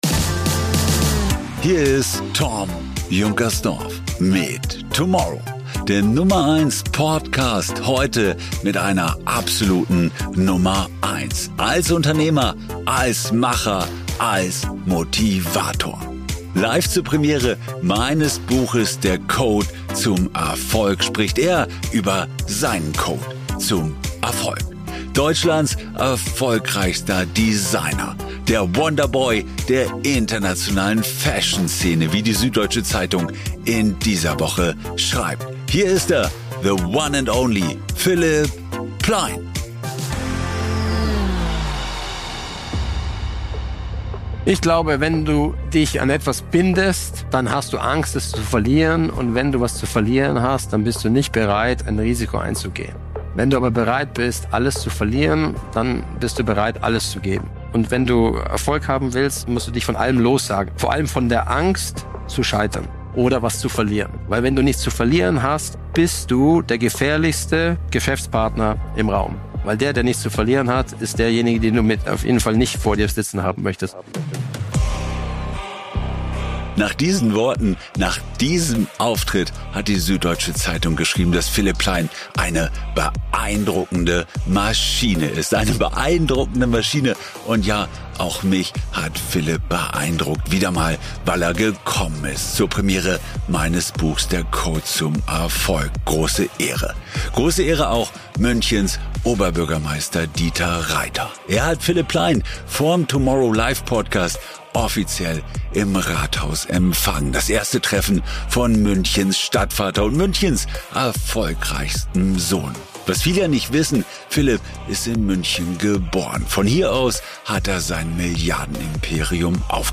Aufgenommen live bei der Buchpremiere in der CUPRA City Garage München. Full House.